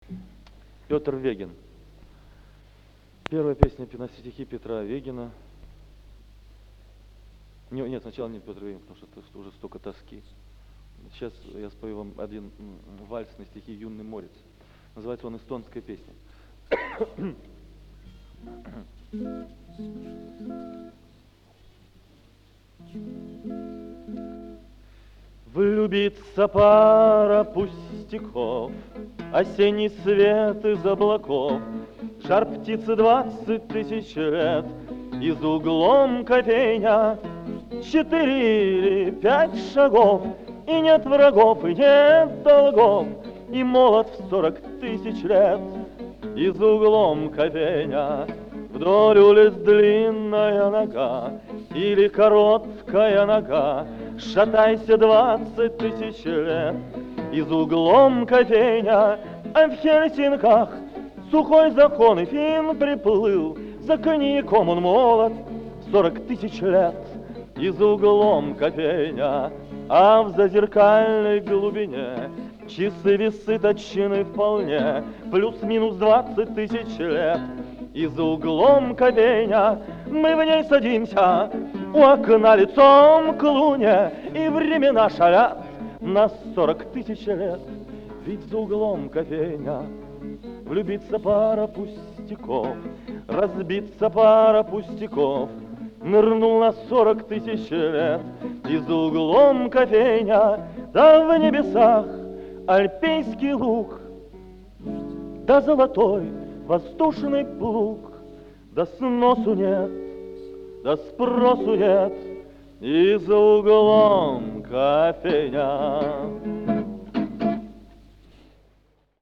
Это старая-старая запись, оцифрованная еще с катушки.